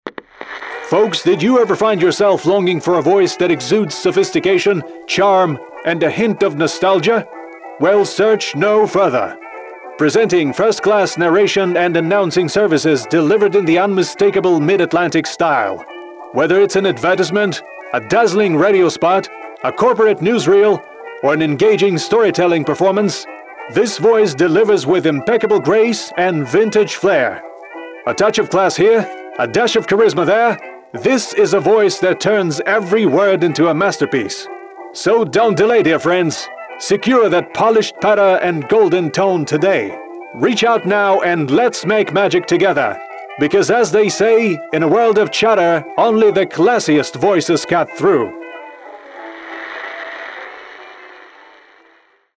Male
Radio Commercials
Transatlantic / Mid-Atlantic
1204Mid-Atlantic_Announcer.mp3